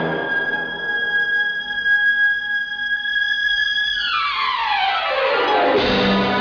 high-pitch cue